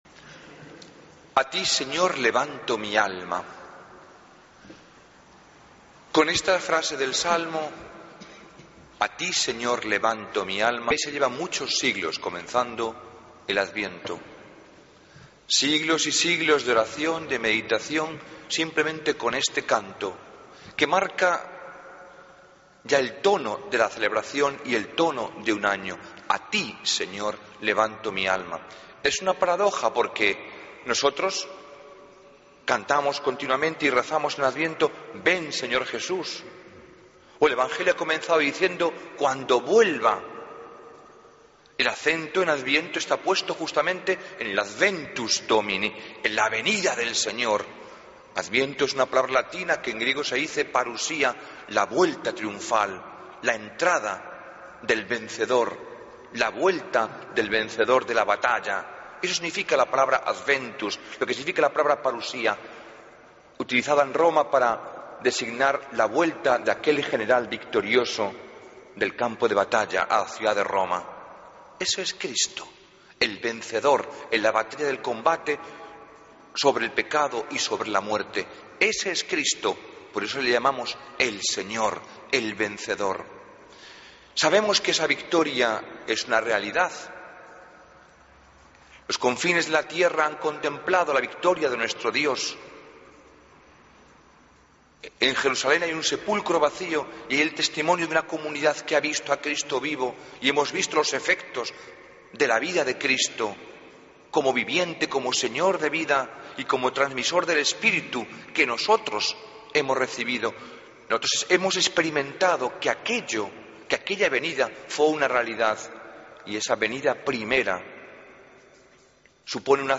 Homilía del Domingo 1 de Diciembre de 2013